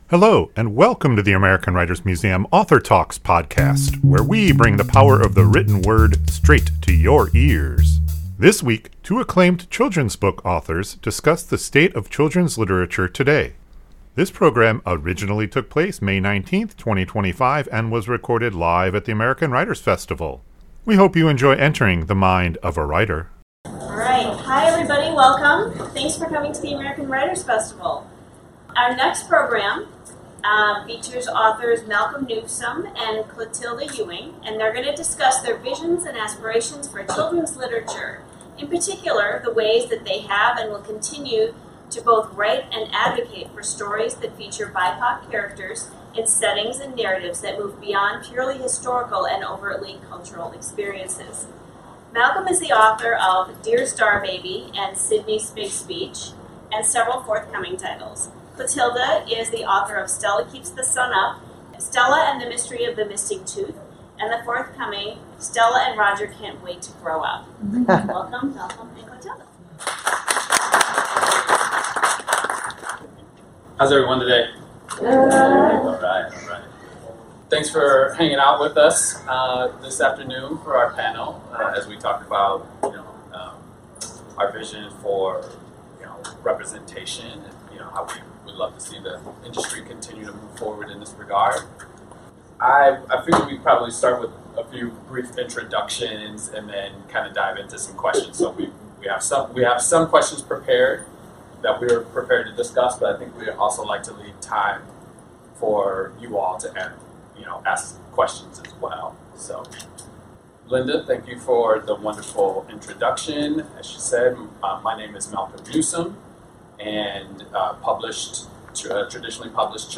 In particular, ways they have and will continue to both write and advocate for stories that feature BIPOC characters in settings and narratives that move beyond purely historical and overtly "cultural" experiences. This conversation originally took place May 19, 2025 and was recorded live at the 2024 American Writers Festival.